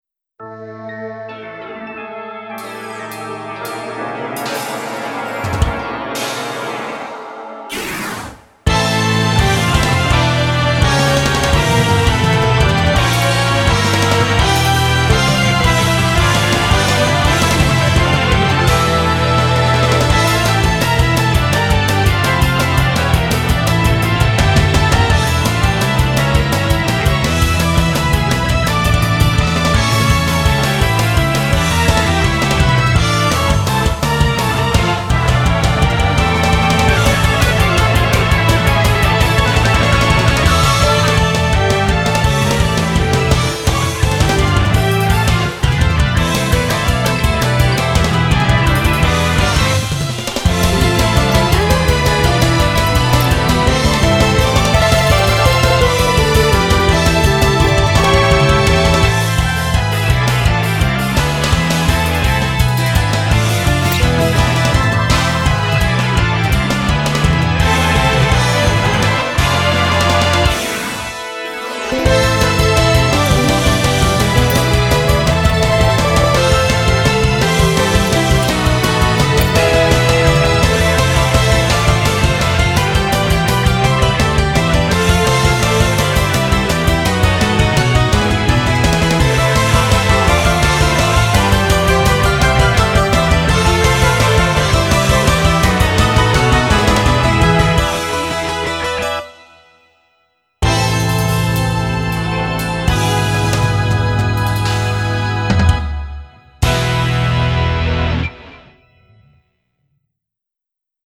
BPM140-168
Comments[GOTHIC METAL]